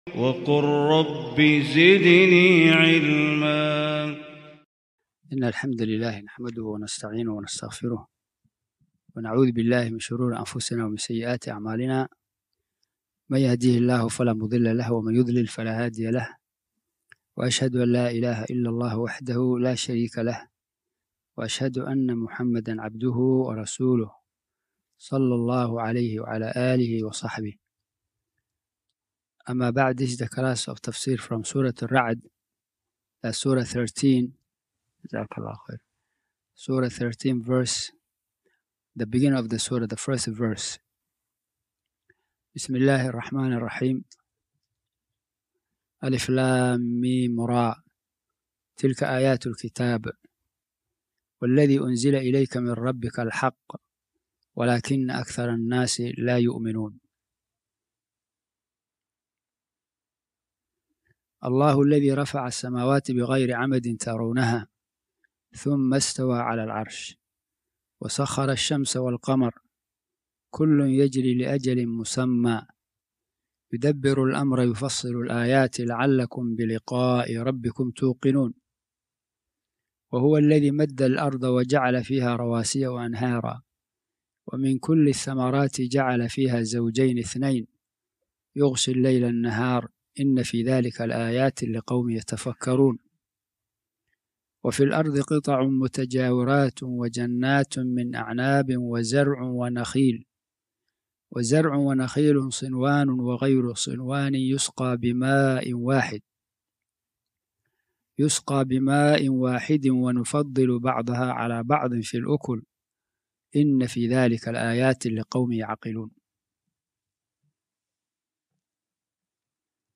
Surah Ar-Ra’d (The Thunder) 00:00 Sorry, no results.Please try another keyword Tafsir Quran